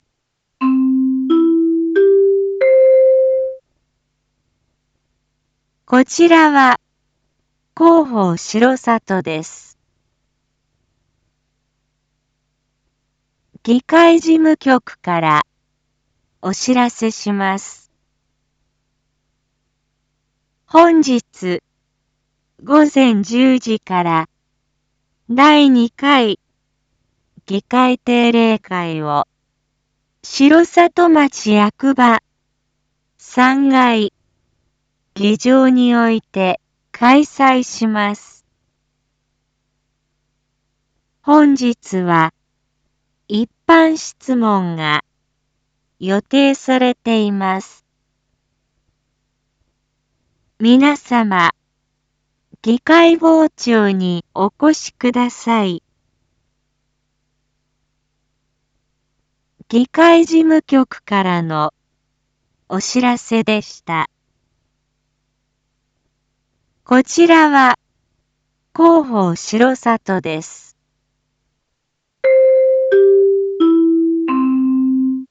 Back Home 一般放送情報 音声放送 再生 一般放送情報 登録日時：2023-06-08 07:01:16 タイトル：6/8朝 議会 インフォメーション：こちらは広報しろさとです。